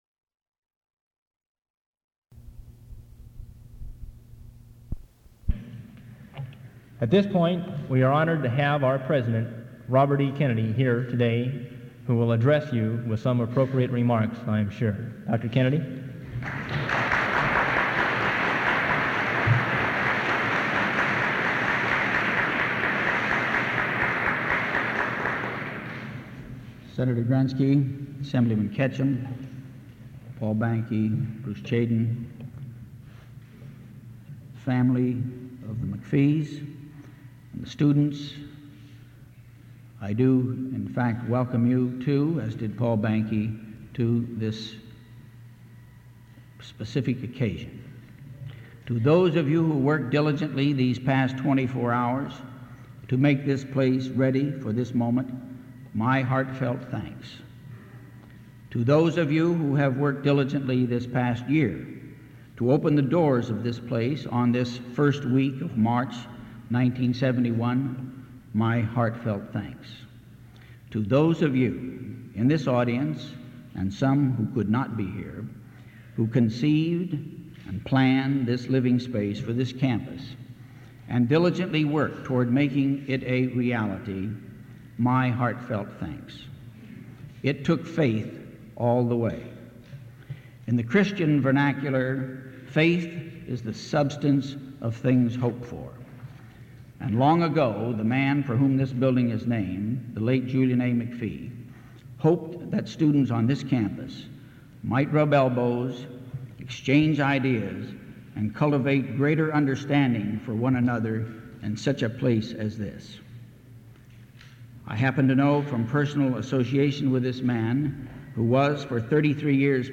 Kennedy addresses the audience and those who worked to open the building
Benediction prayer
Form of original Audiocassette